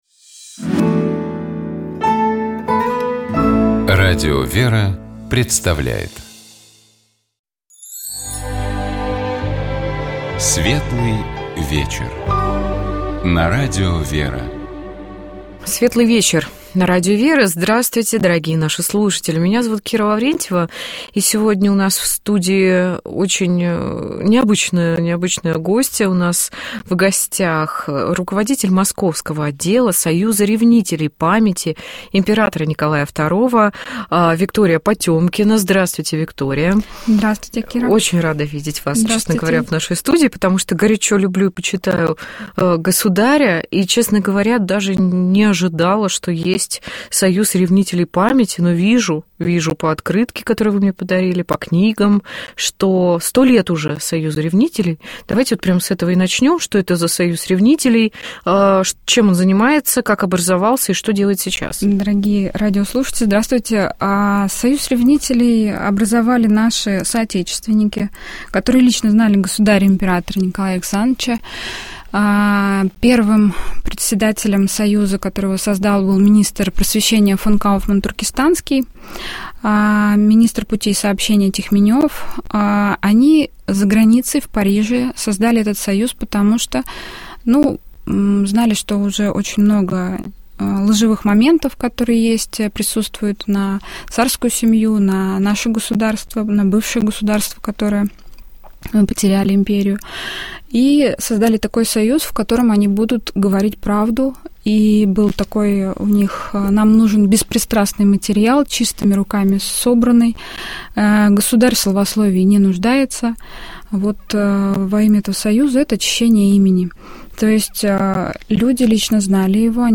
«Светлый вечер» на Радио «ВЕРА»